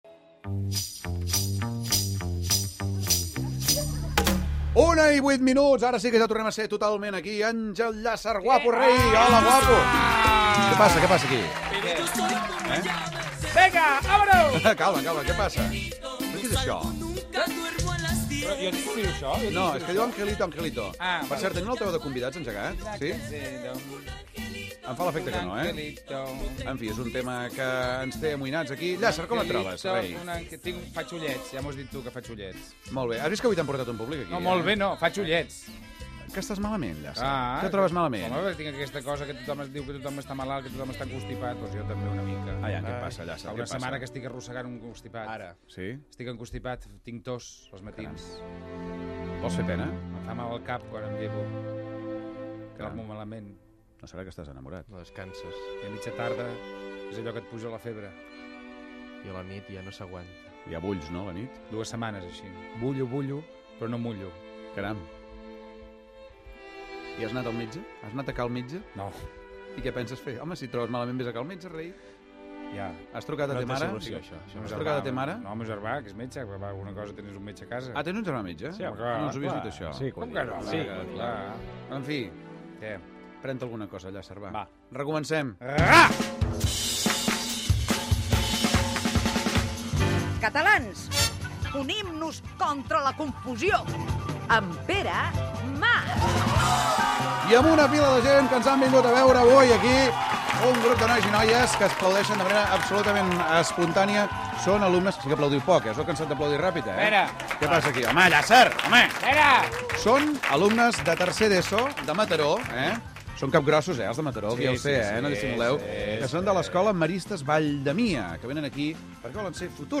Hora, presentació del col·laborador Àngel Llàcer, correu d'una oïdora, "El micro ocult" amb un reportatge del XXXIV Saló d'Arts i Antiguitats de Barcelona, indicatiu, publicitat, "El gran mal de Catalunya" Gènere radiofònic Entreteniment